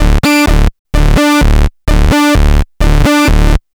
Session 11 - Bass 05.wav